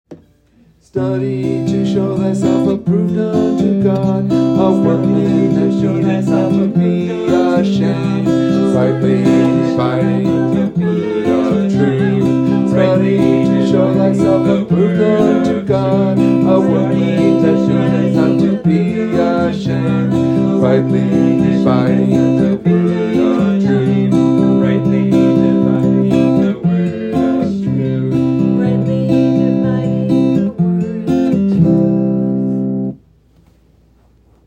sung as a round